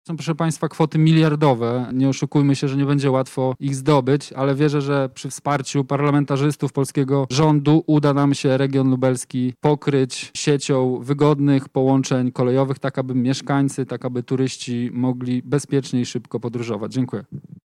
Chcemy rozwijać połączenia kolejowe i inwestować w nową infrastrukturę– mówi Wicemarszałek Województwa Lubelskiego Michał Mulawa: